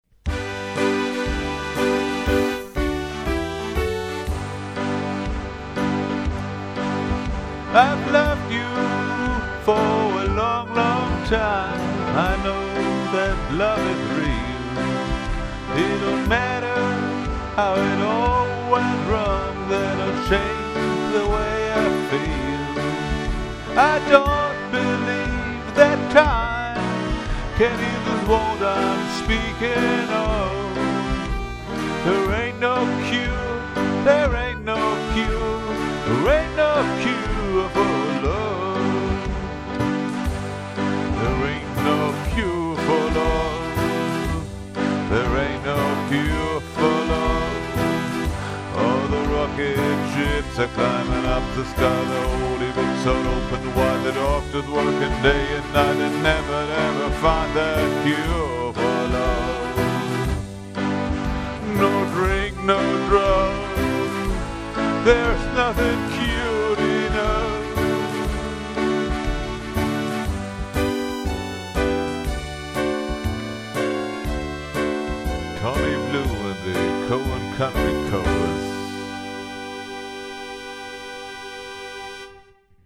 Demo Version